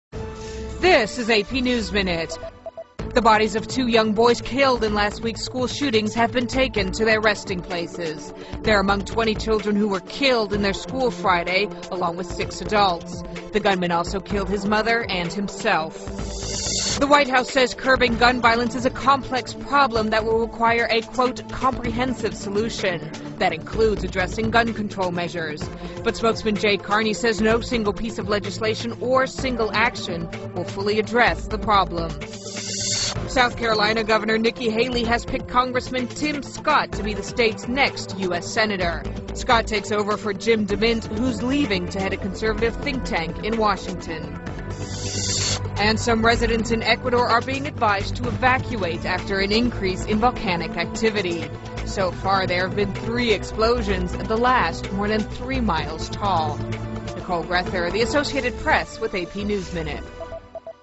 在线英语听力室美联社新闻一分钟 AP 2012-12-20的听力文件下载,美联社新闻一分钟2012,英语听力,英语新闻,英语MP3 由美联社编辑的一分钟国际电视新闻，报道每天发生的重大国际事件。电视新闻片长一分钟，一般包括五个小段，简明扼要，语言规范，便于大家快速了解世界大事。